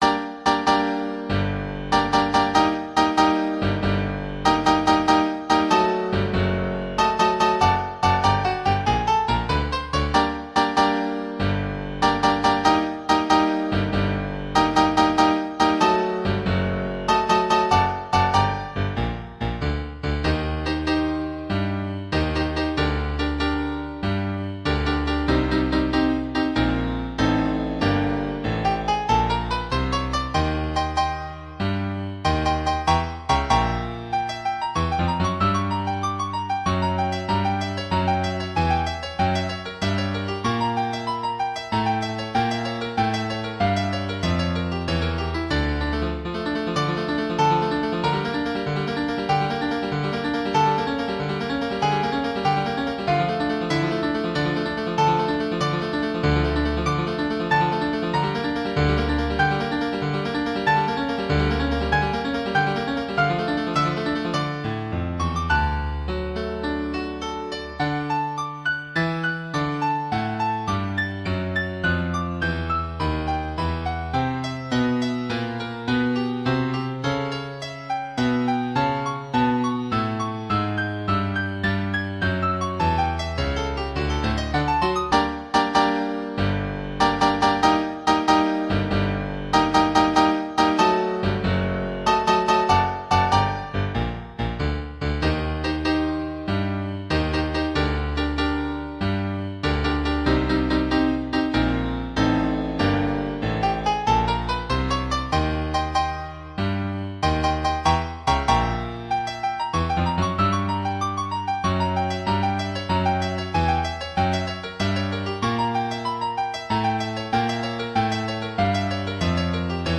Драматичная музыка для черно-белого кино с титрами